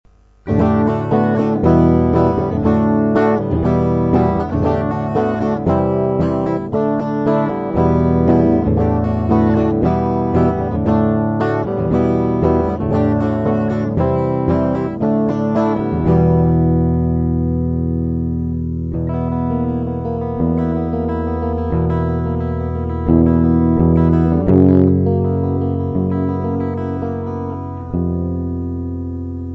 Проигрыш (D - Em - D - Em - D - C - Dsus2 - Em):